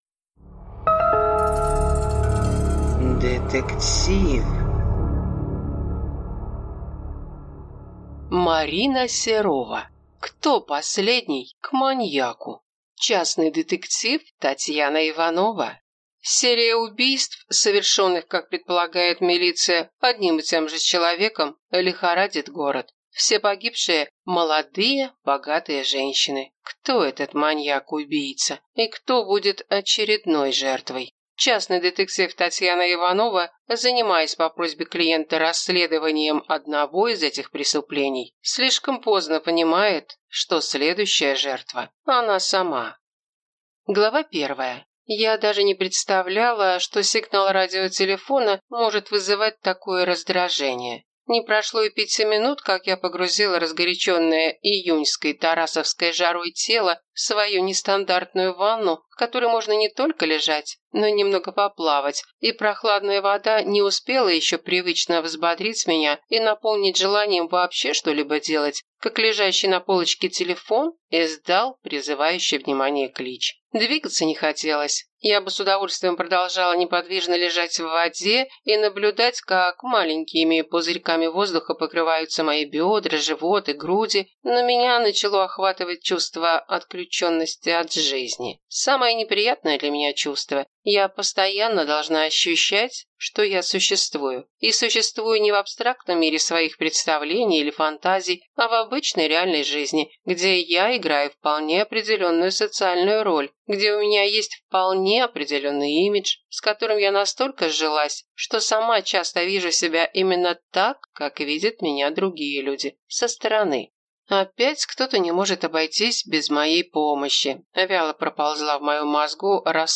Аудиокнига Кто последний к маньяку?